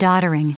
Prononciation du mot doddering en anglais (fichier audio)
Prononciation du mot : doddering